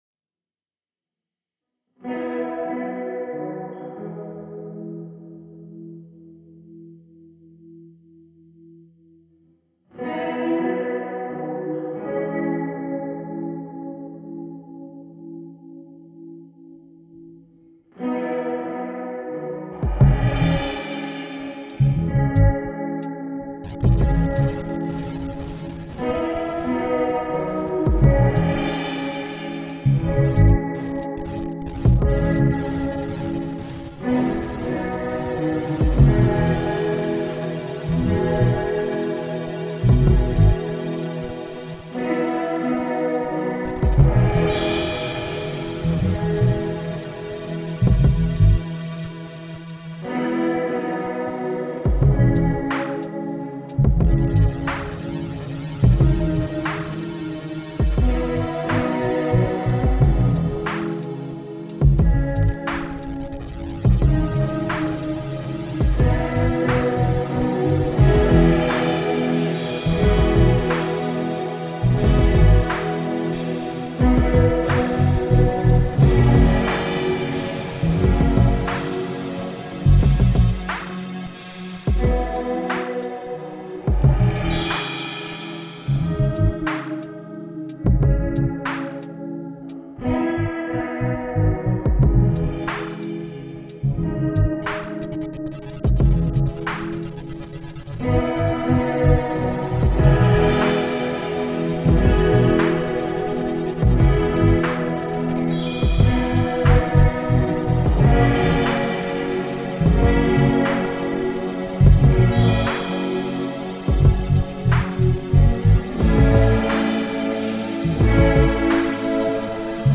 My brother got me a stylophone and this happened.